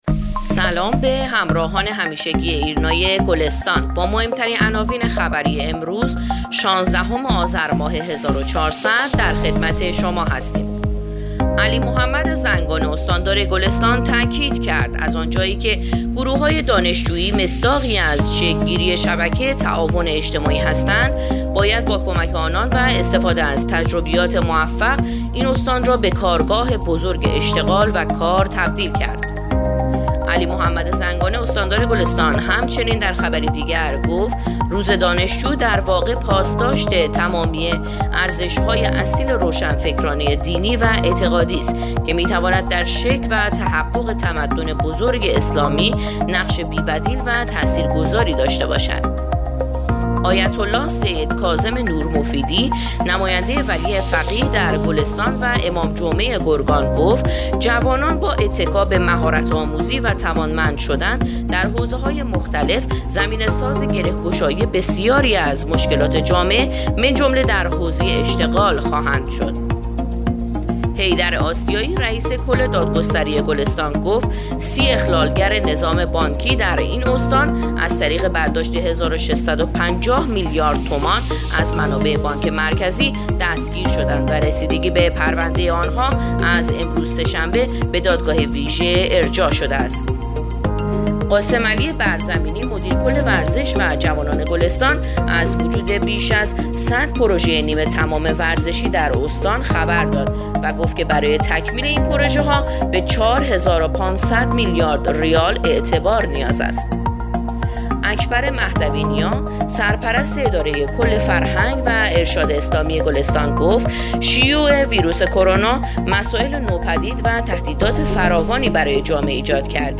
پادکست/ اخبار شامگاهی شانزدهم آذر ایرنا گلستان